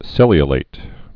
(sĭlē-ə-lāt)